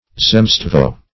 Zemstvo \Zem"stvo\, n. [Russ., fr. zemlya land.]